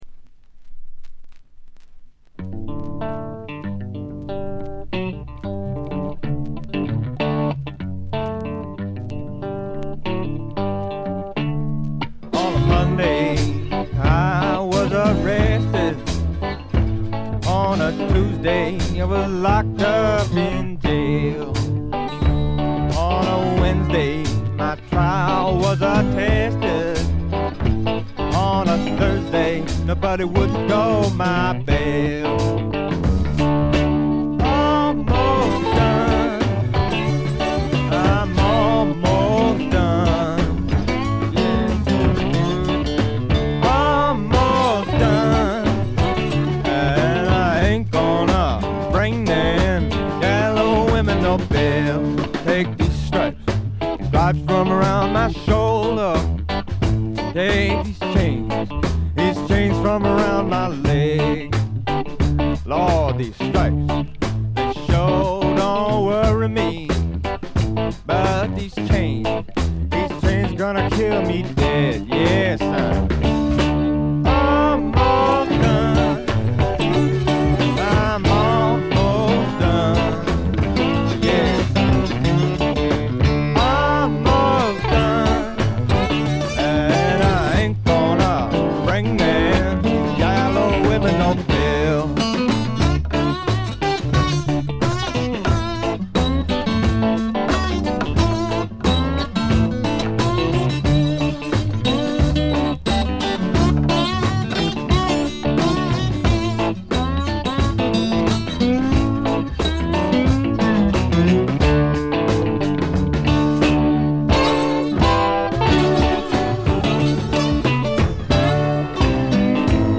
軽微なチリプチがわずかに出る程度。
試聴曲は現品からの取り込み音源です。
guitar, bass, mandolin, vocals